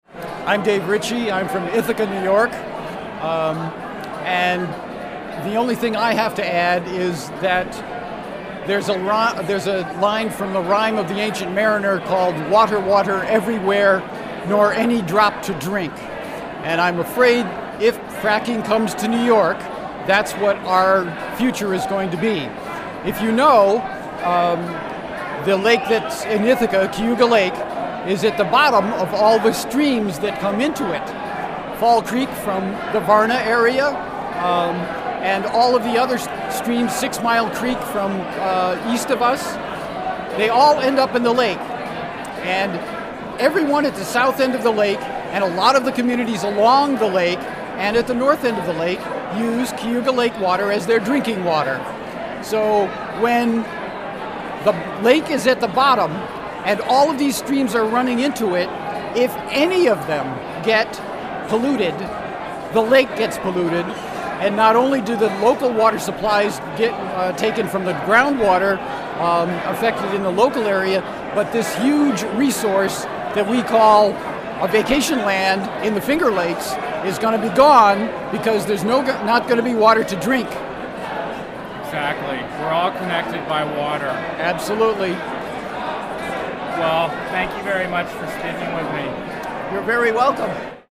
(1:31) Recorded at the State of the State, Empire State Plaza, Albany.